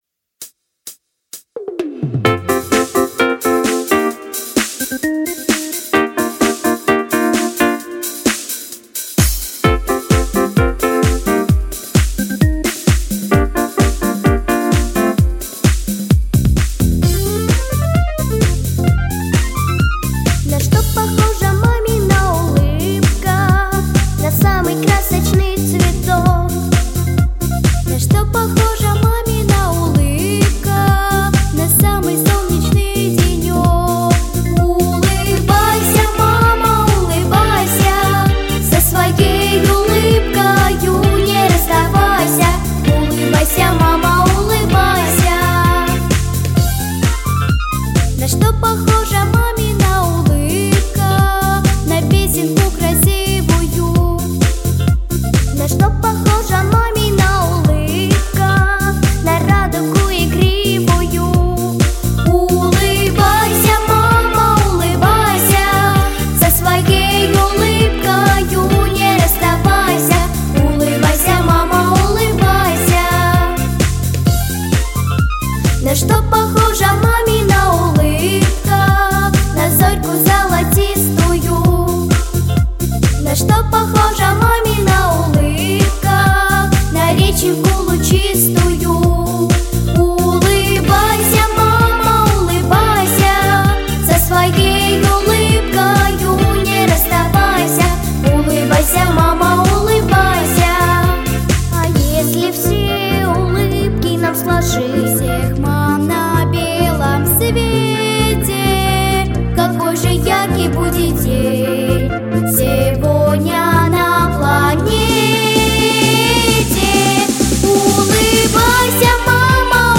Песни про маму